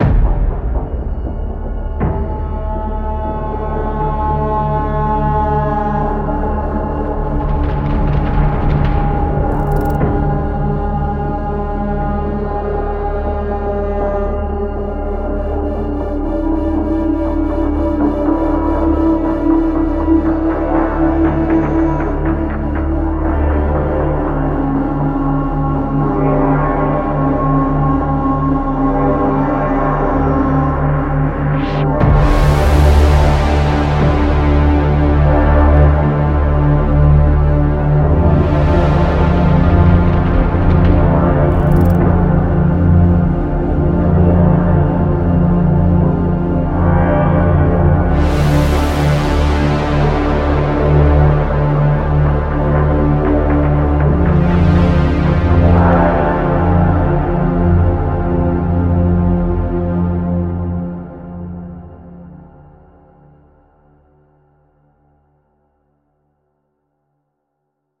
Cinematic 1